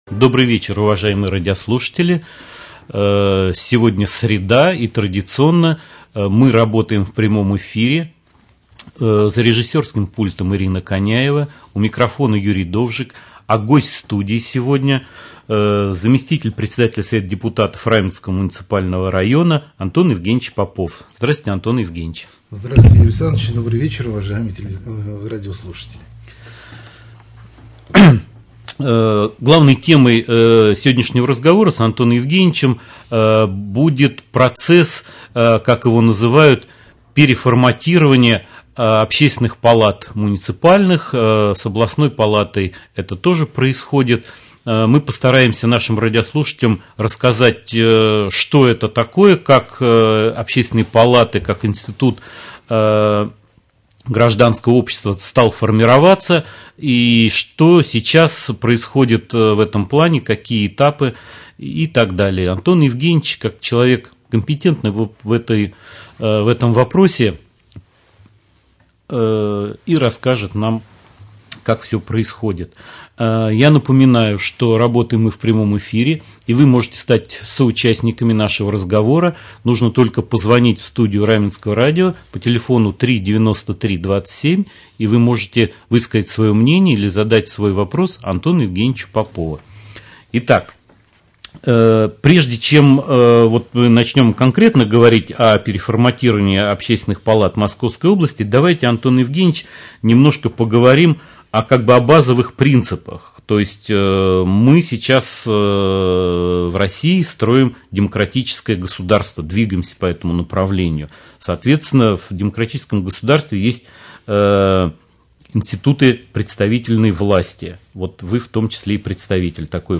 Прямой эфир с заместителем председателя Совета депутатов Раменского муниципального района Антон Евгеньевич Попов.